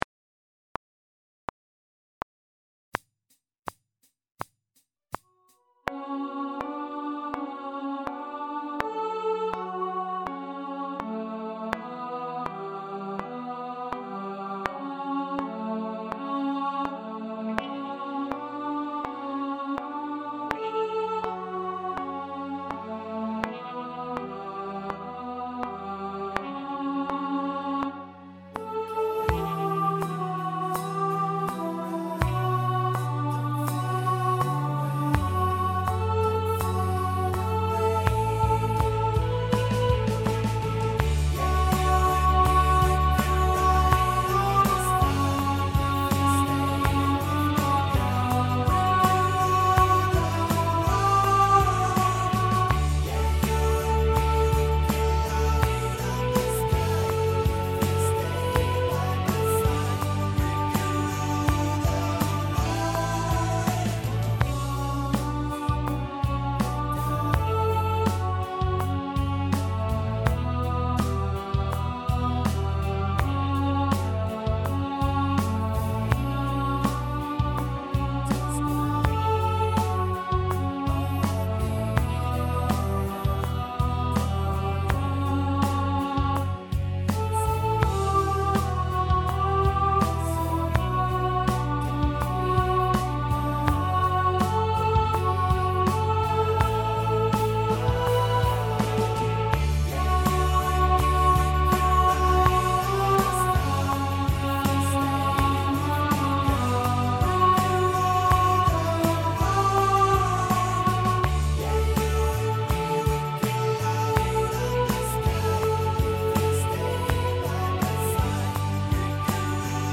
Rule The World – Alto | Ipswich Hospital Community Choir